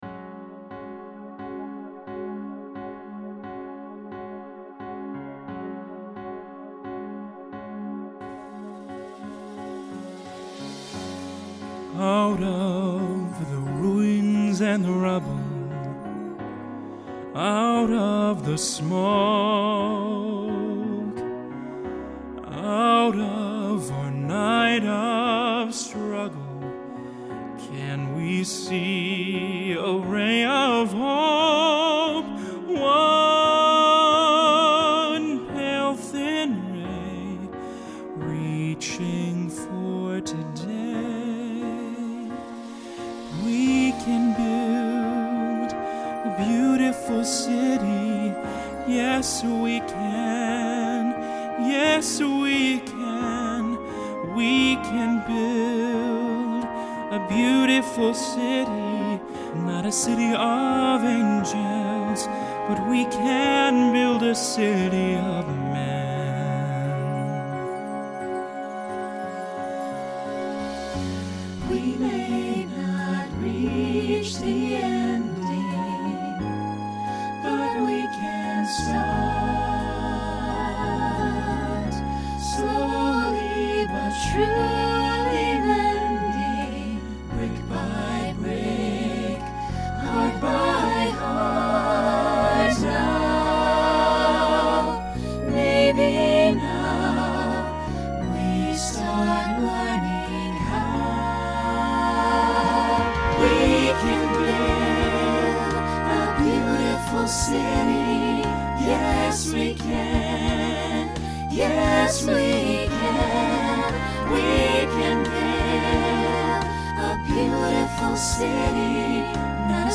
New TTB voicing for 2026.